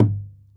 SingleHit_QAS10780.WAV